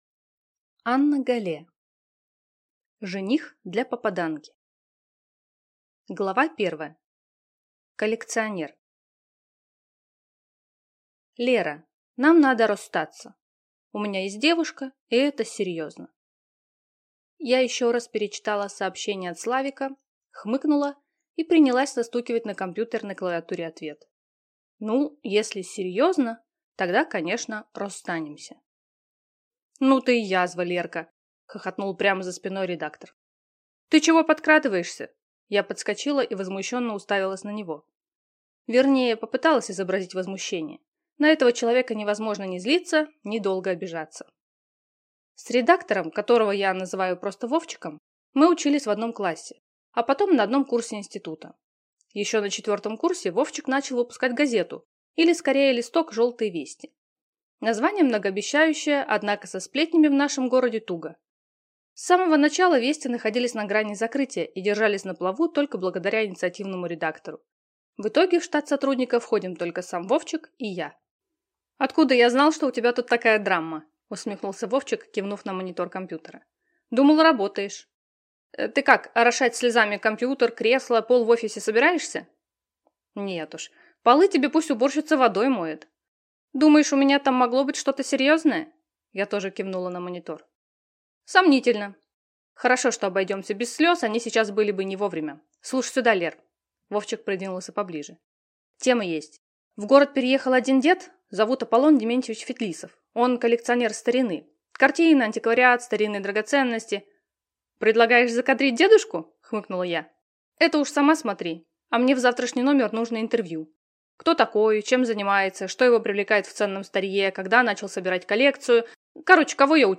Аудиокнига Жених для попаданки | Библиотека аудиокниг